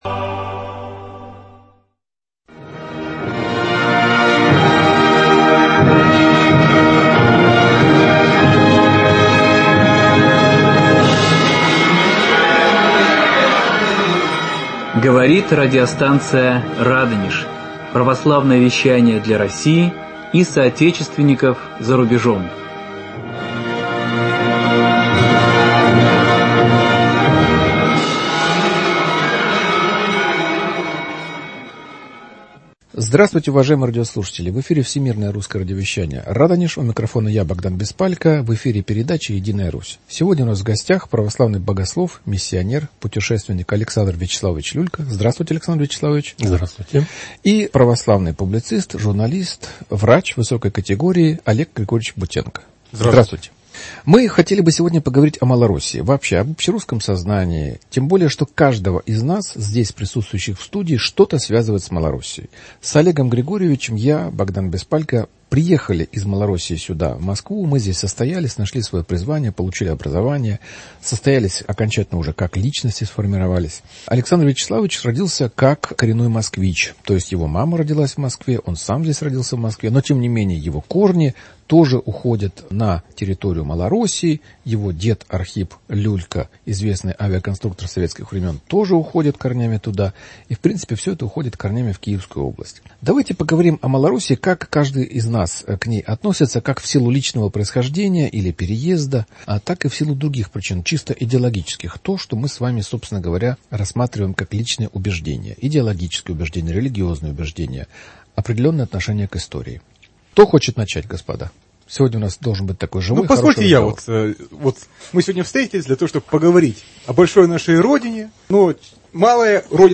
В студии радио